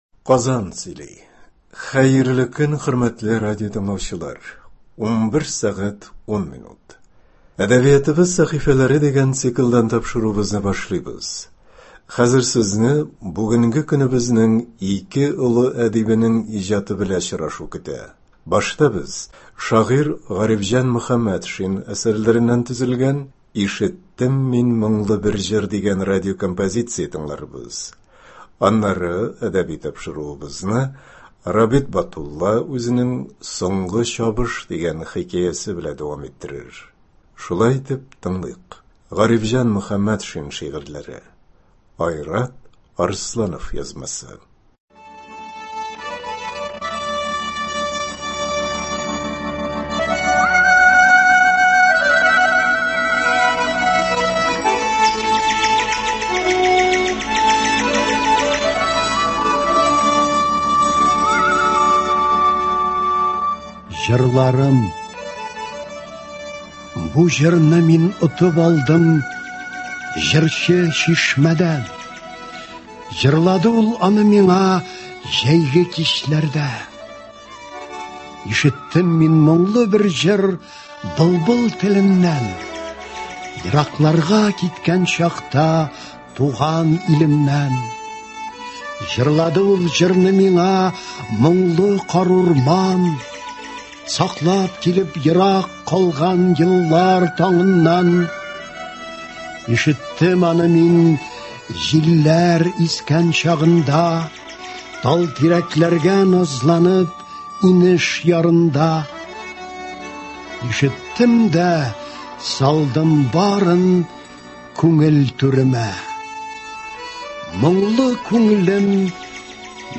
авторның үзе укуында яңгырый.